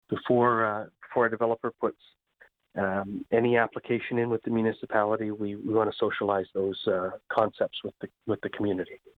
Mayor Brian Ostrander says it’s important the public get involved early in the process as Brighton continues to grow.